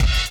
JB KICK 2.wav